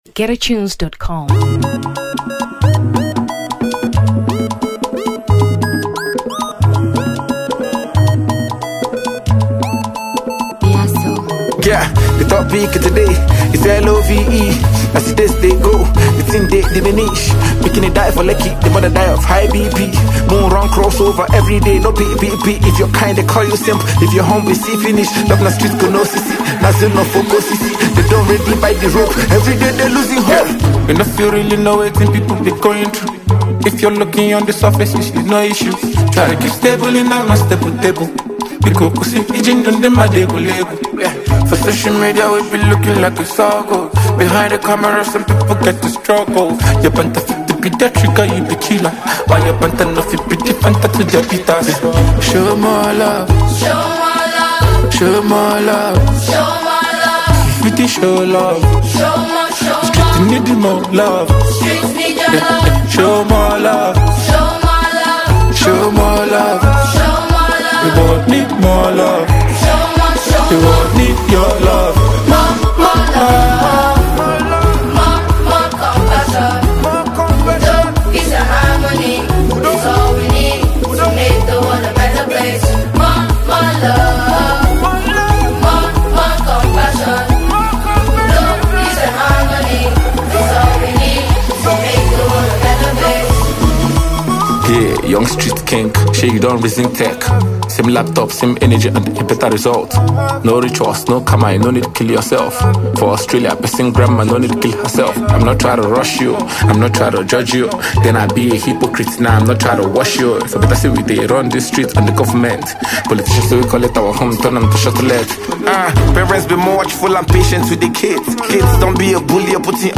Afrobeats 2023 Malawi